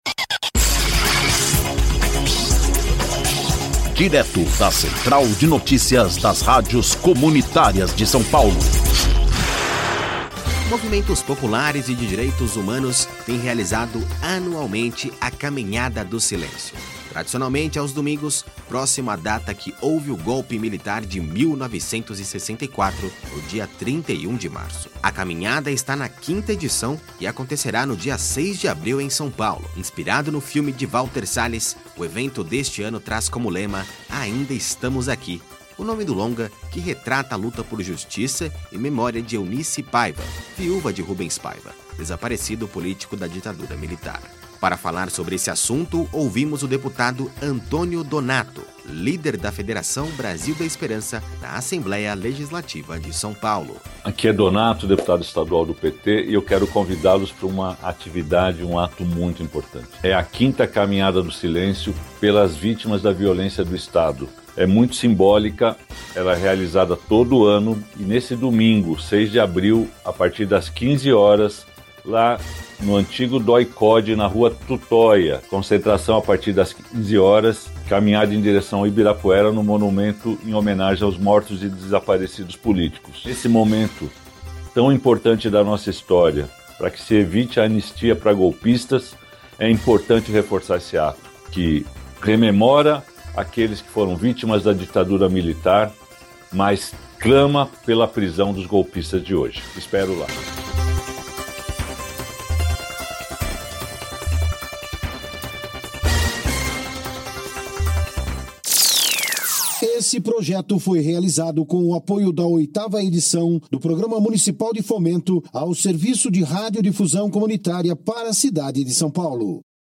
Para falar sobre este assunto ouvimos o deputado Antonio Donato líder da federação Brasil da Esperança na Assembleia Legislativa de São Paulo.